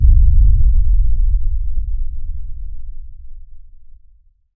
cinematic_deep_bass_rumble_02.wav